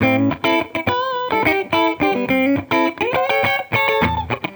Index of /musicradar/sampled-funk-soul-samples/105bpm/Guitar
SSF_TeleGuitarProc1_105A.wav